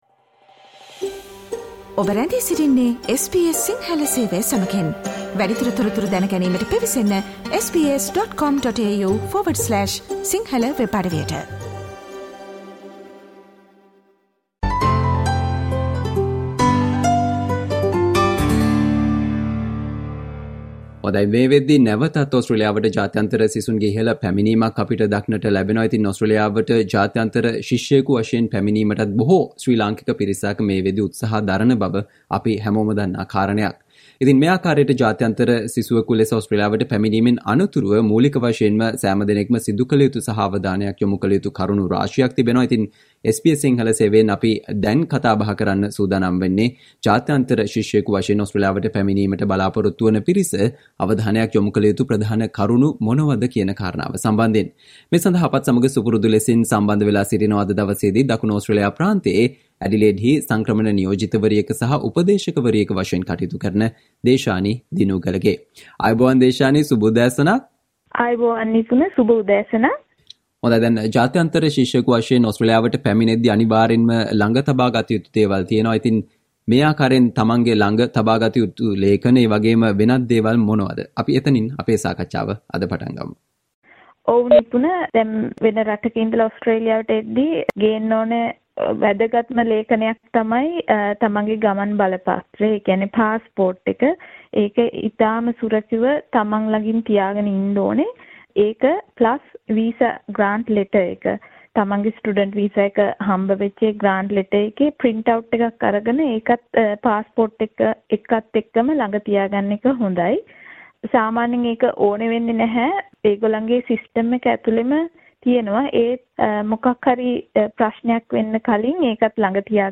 මේ වනවිට ඕස්ට්‍රේලියාවට ජාත්‍යන්තර ශිෂ්‍ය වීසා යටතේ පැමිණීමට බලාපොරොත්තු වන සහ පැමිණෙන පිරිස අවධානයක් යොමුකලයුතු කරුණු පිළිබඳව SBS සිංහල සේවය සිදු කල සාකච්චාවට සවන්දෙන්න